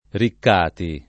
[ rikk # ti ]